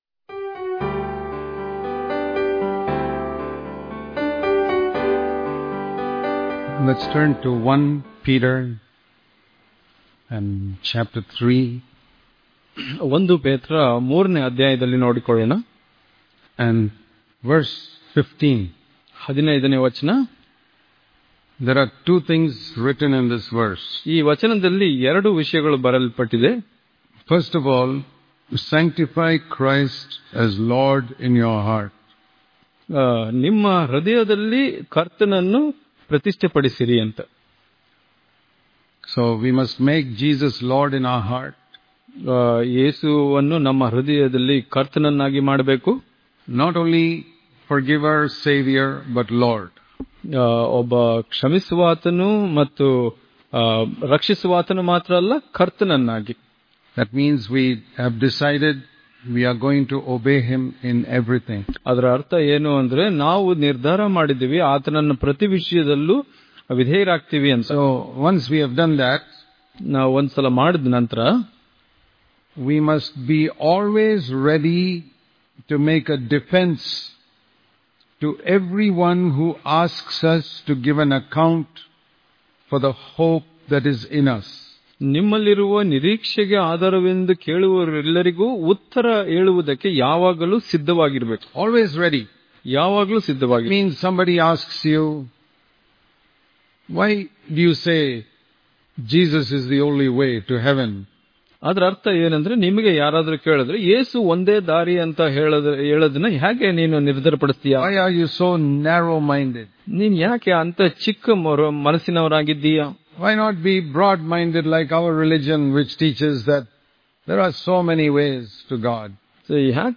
December 22 | Kannada Daily Devotion | Why We Believe Jesus Is The Only Way - Part 1 Daily Devotions